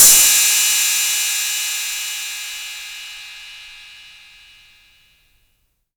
808CY_5_TapeSat.wav